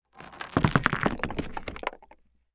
Sand_Pebbles_25.wav